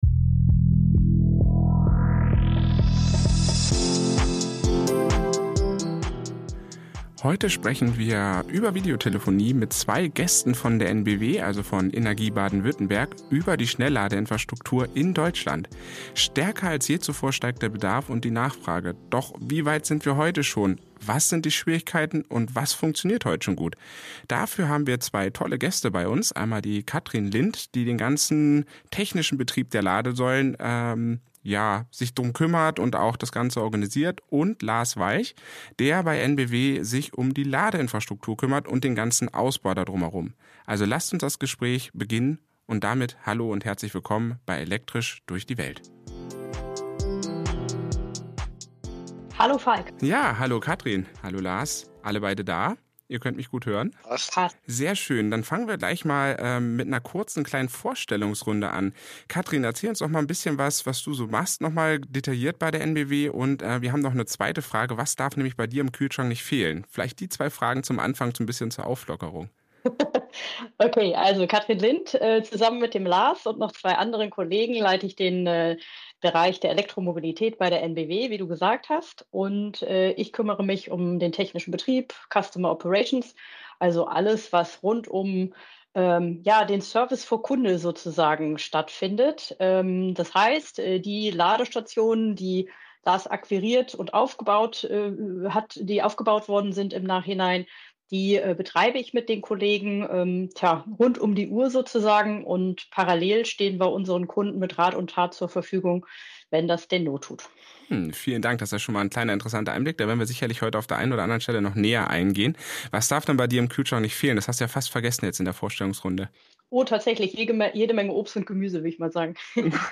(im Gespräch mit EnBW) ~ Voltage – Erneuerbare Mobilität & Energie Podcast